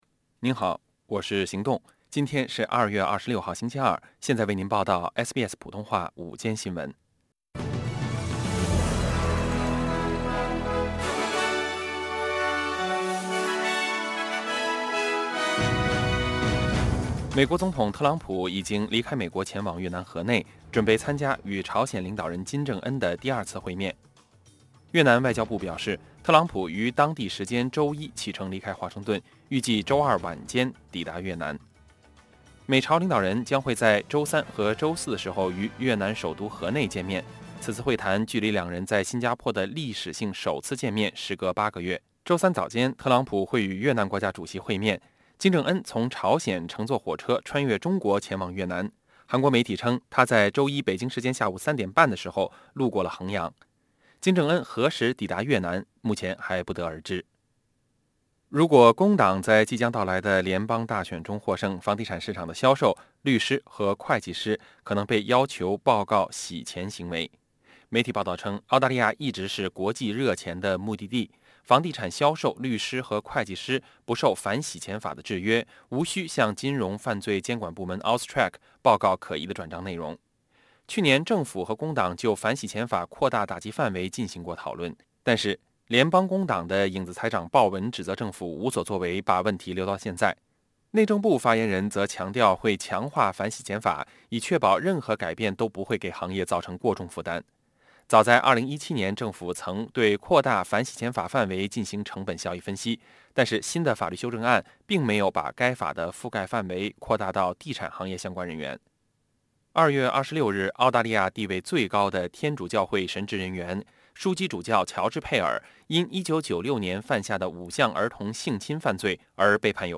SBS午间新闻（2月26日）